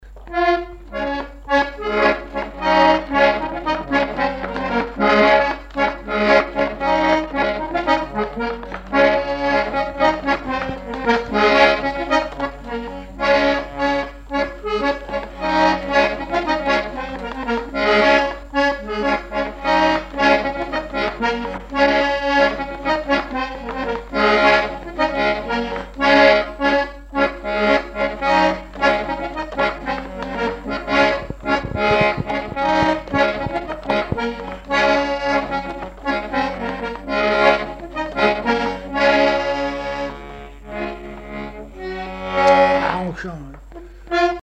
Couplets à danser
branle : courante, maraîchine
Répertoire instrumental à l'accordéon diatonique
Pièce musicale inédite